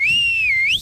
call_pet.ogg